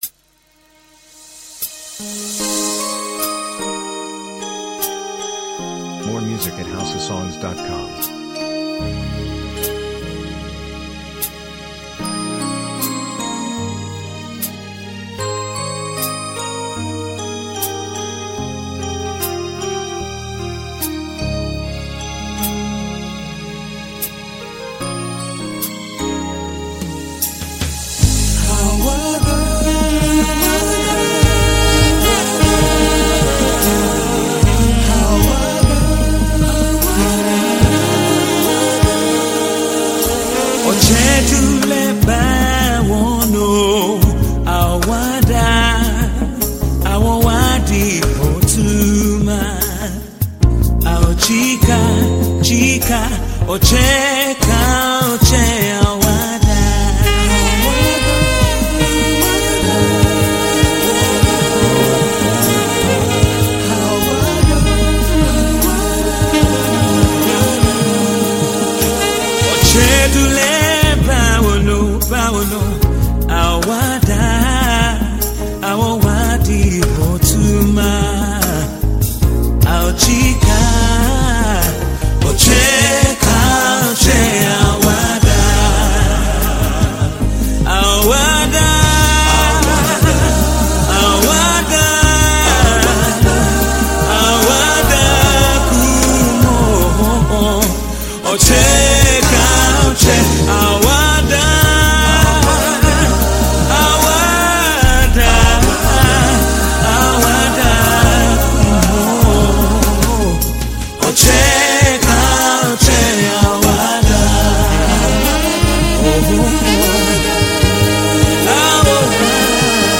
encouraging, uplifts the spirit and soul
Your desired Tiv song